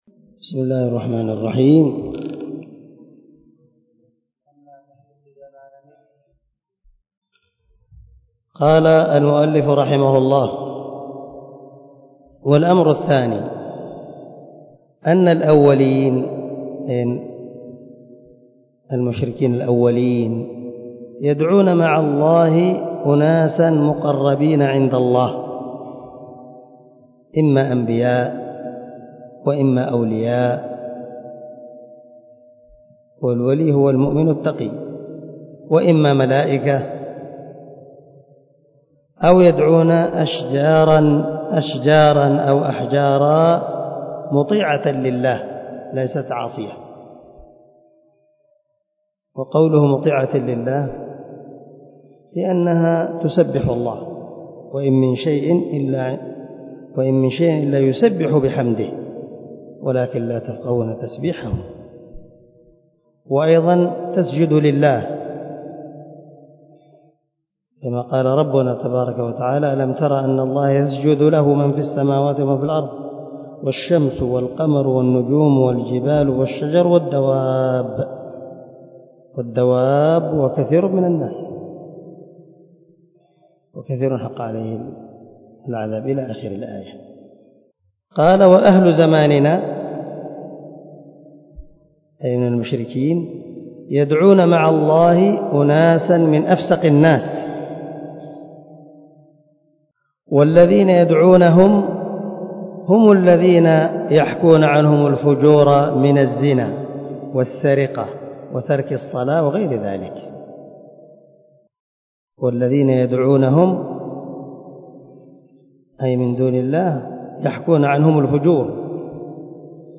شرح كشف الشبهات 0015الدرس 14 من شرح كتاب كشف الشبهات